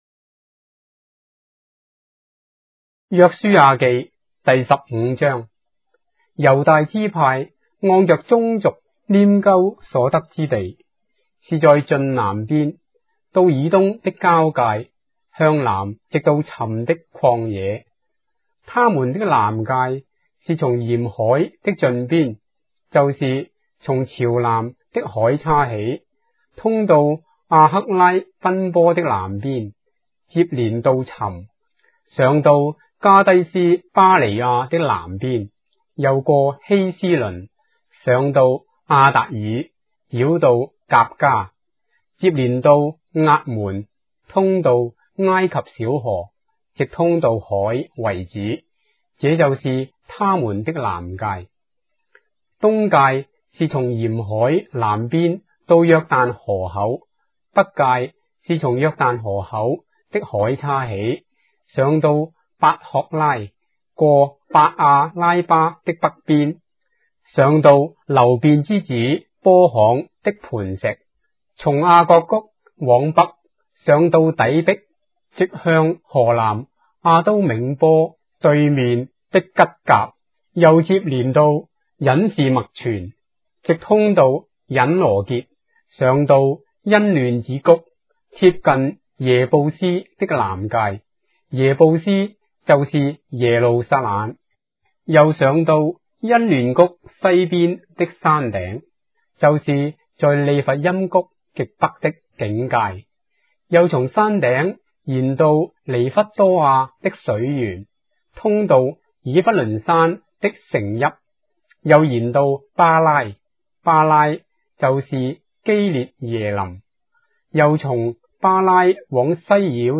章的聖經在中國的語言，音頻旁白- Joshua, chapter 15 of the Holy Bible in Traditional Chinese